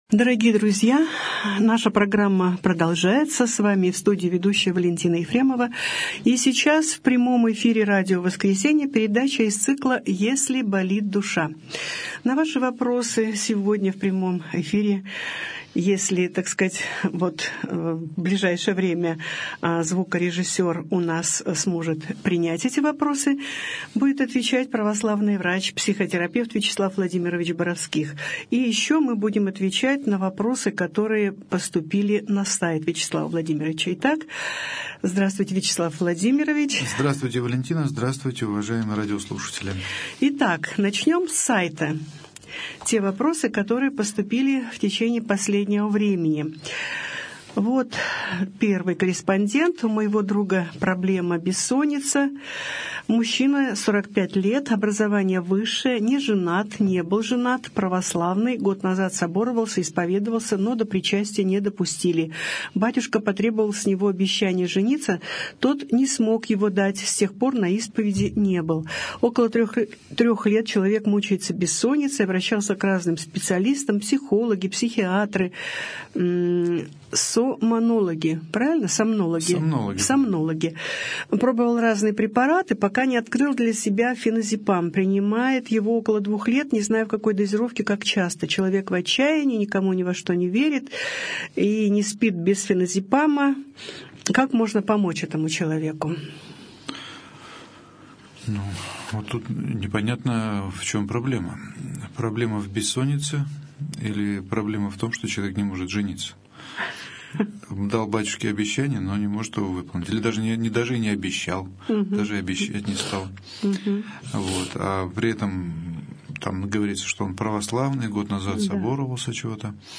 О душевном здоровье. Беседа с психологом | Православное радио «Воскресение»